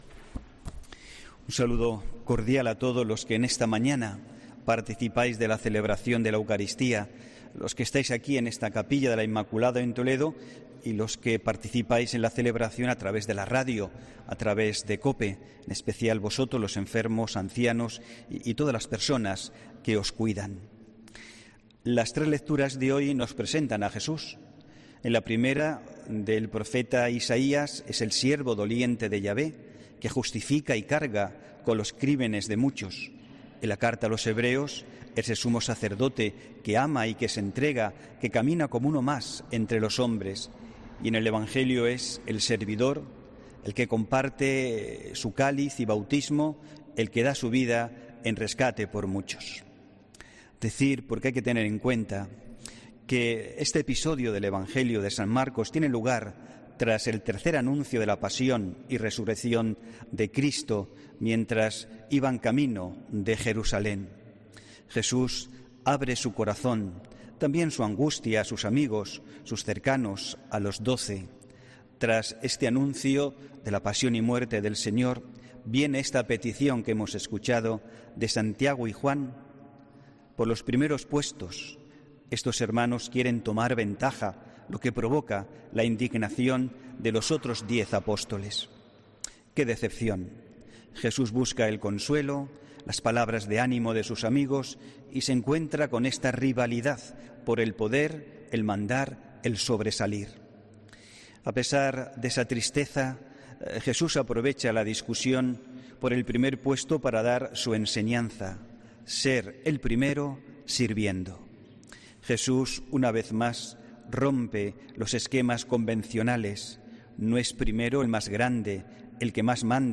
HOMILÍA 17 OCTUBRE 2021